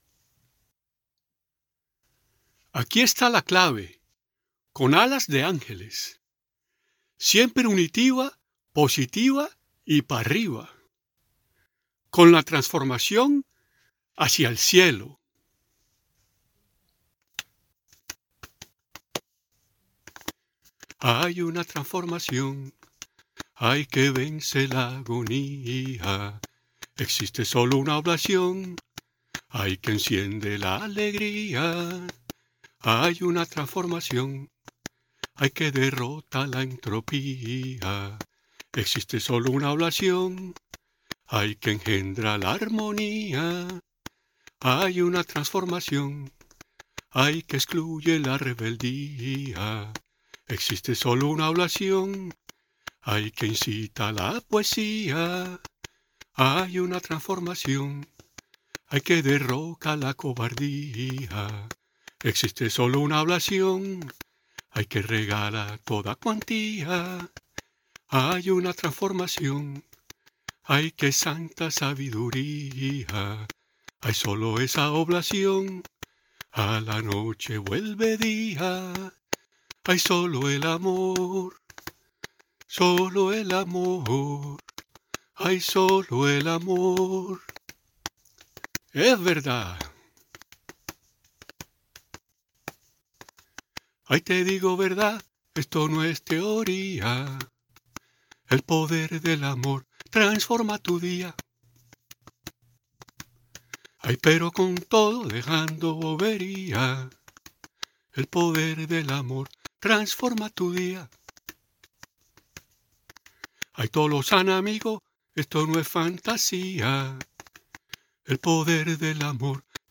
La canción relacionada “La transformación” se puede escuchar a capela aquí: